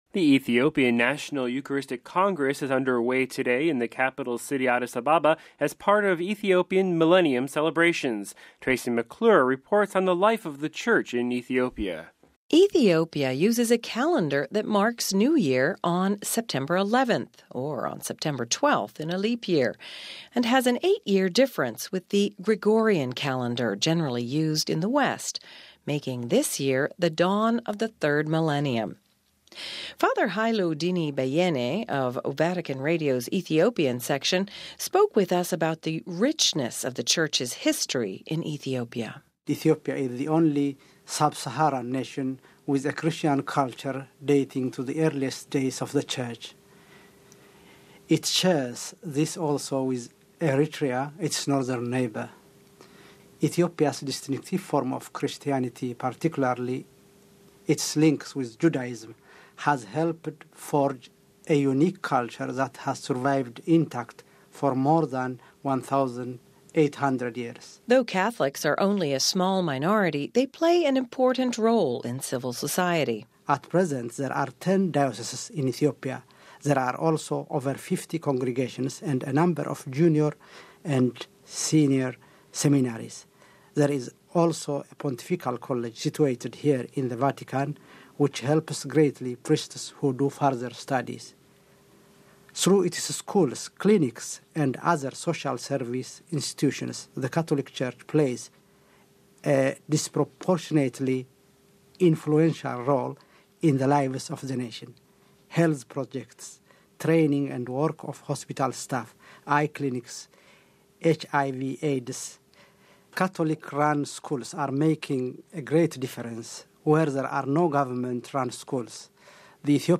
reports on the life of the Church in Ethiopia…